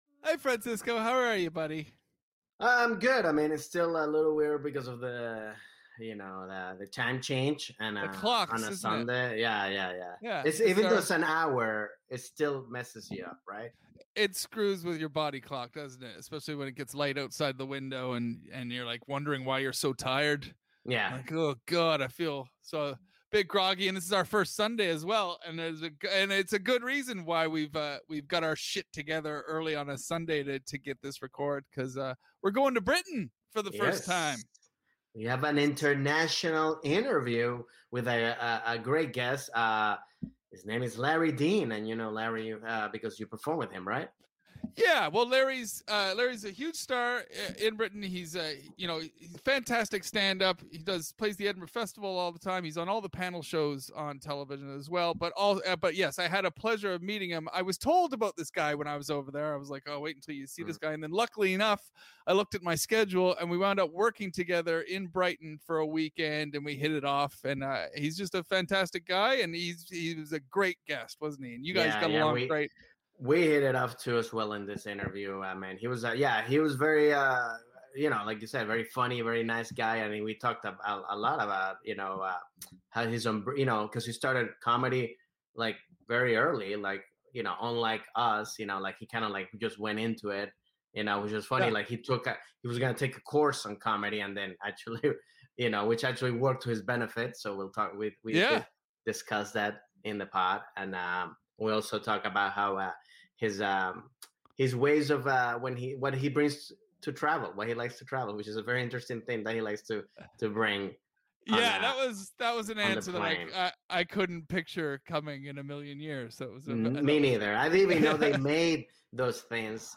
This week, award winning Scottish comedian LARRY DEAN drops in to chat with the guys about accents, squatty potties, and small furry creatures!